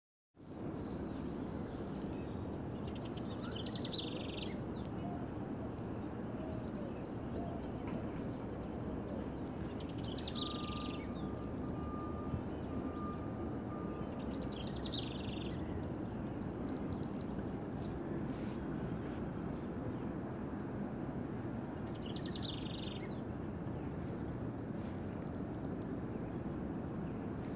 Piojito Común (Serpophaga subcristata)
Nombre en inglés: White-crested Tyrannulet
Fase de la vida: Adulto
Localidad o área protegida: Reserva Ecológica Costanera Sur (RECS)
Condición: Silvestre
Certeza: Fotografiada, Vocalización Grabada